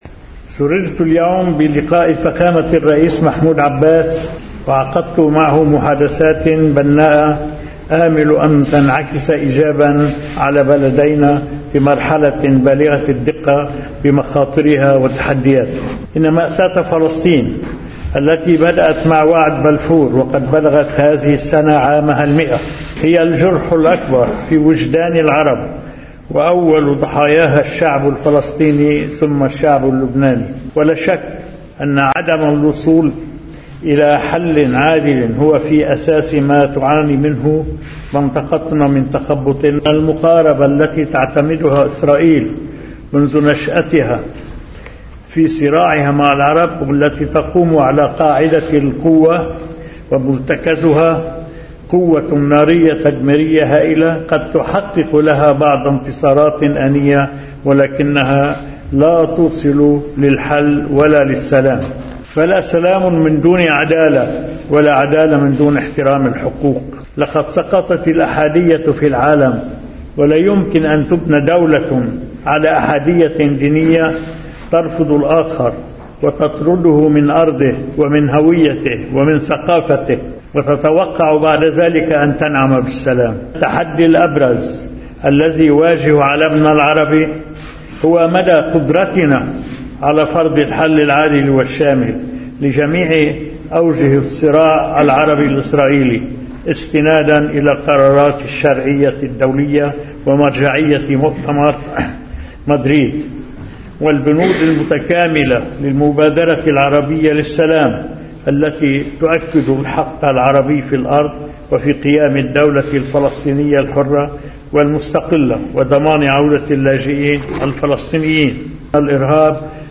مقتطف من المؤتمر الصحفي المشترك للرئيس عون والفلسطيني محمود عباس في بعبدا: